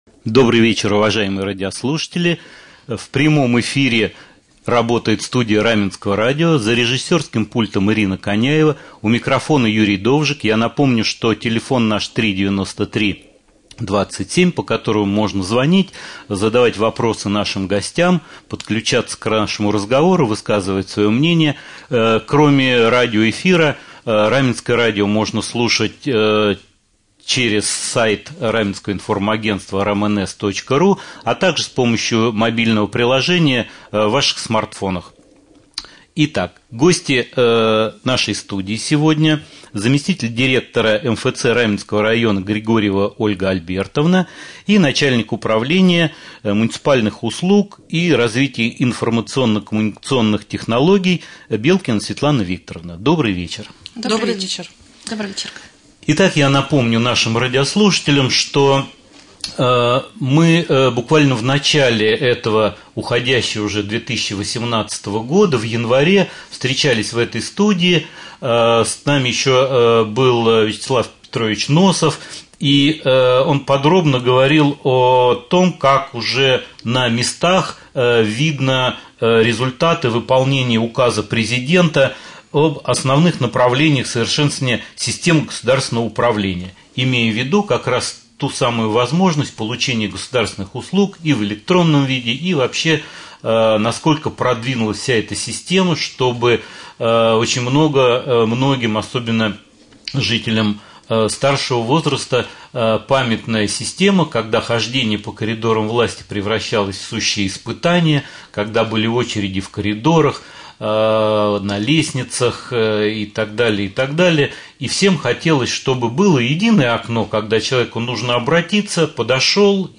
2.Прямой эфир.